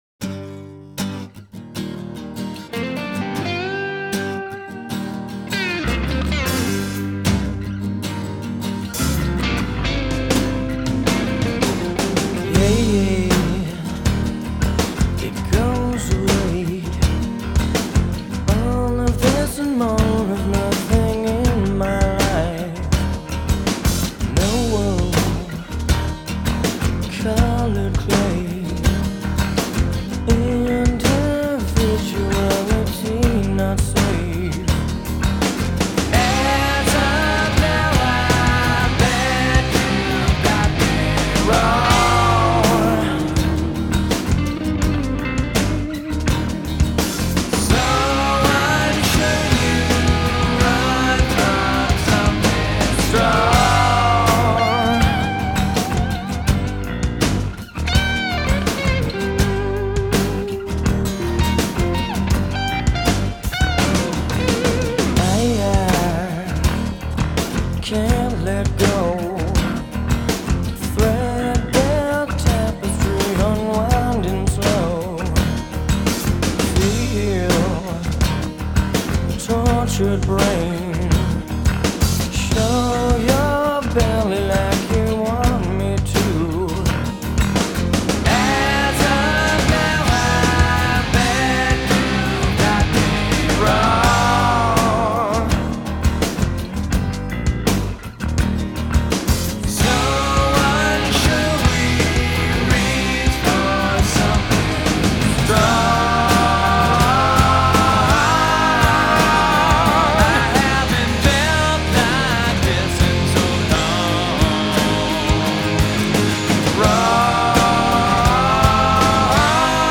grunge
acoustic rock